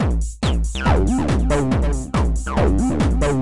描述：在ReBirthrb338中创建的140bpm循环
Tag: 房子 重生 TB303 tr808 tr909